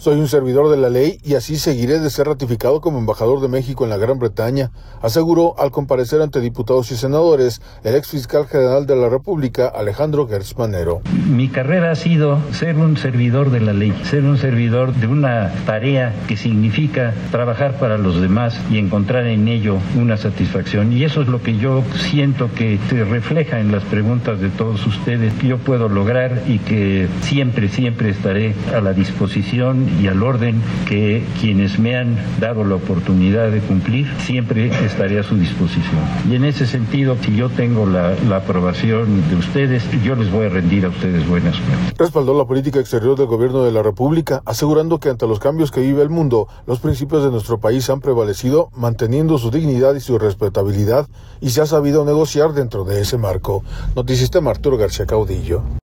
Soy un servidor de la Ley y así seguiré de ser ratificado como embajador de México en la Gran Bretaña, aseguró, al comparecer ante diputados y senadores, el ex fiscal General de la República, Alejandro Gertz Manero.